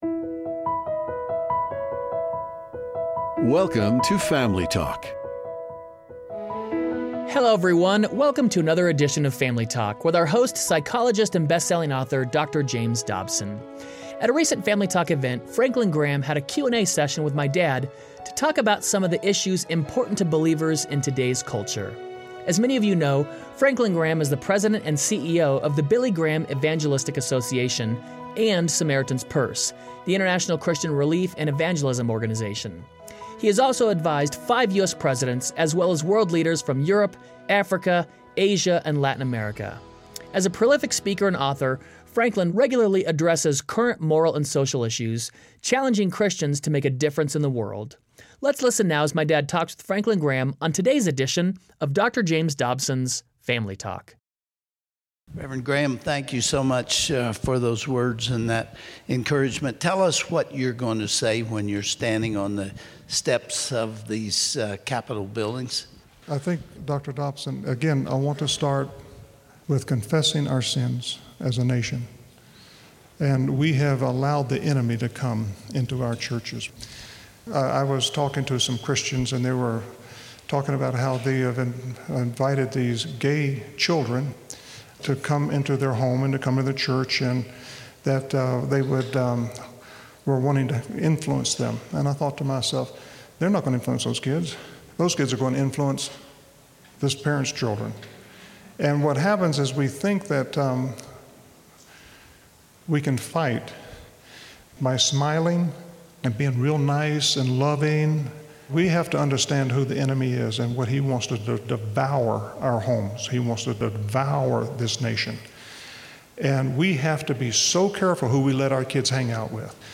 Franklin Graham joins Dr. James Dobson to answer some of the toughest questions facing believers today. You dont want to miss as two of Americas most trusted Christian voices talk about declining values and the changing cultural landscape.